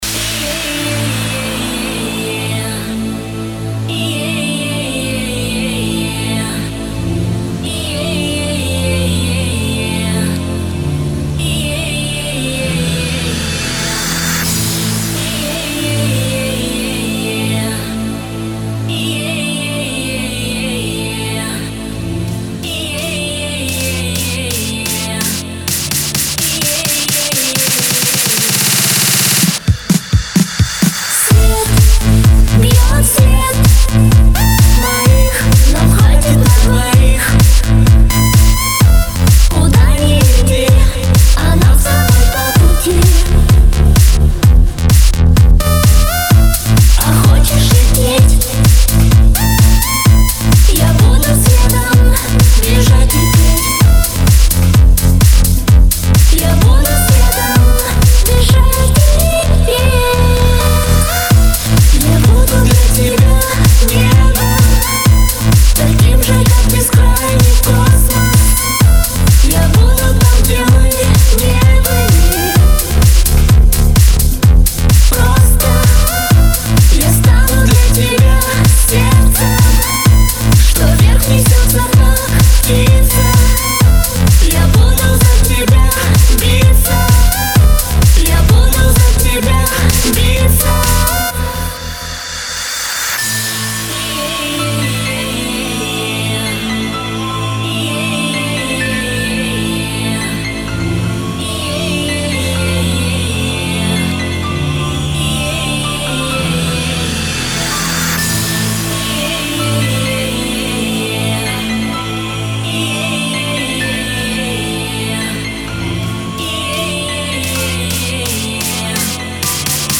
Жанр: House - Electro
лучшая электро хаус музыка